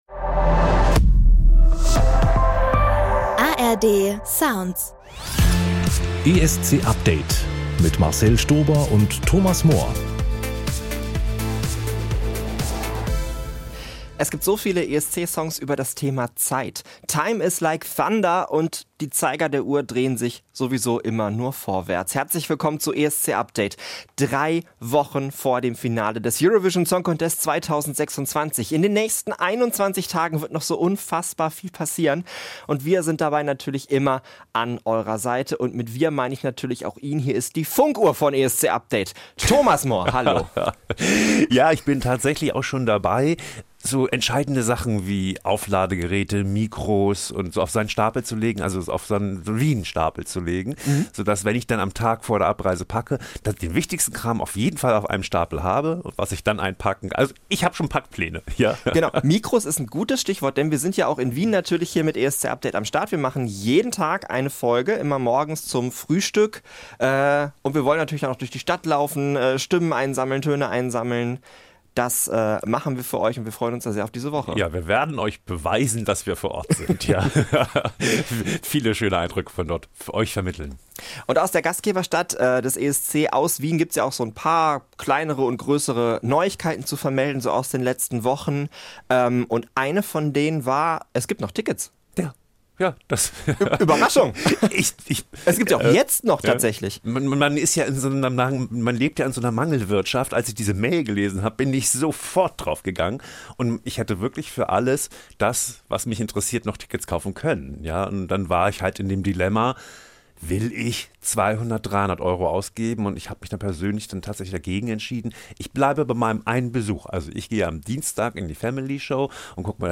Die beiden diskutieren über die sechs Songs, bei denen sie sich überhaupt nicht einig sind - haben aber tatsächlich auch eine gemeinsame Nummer eins.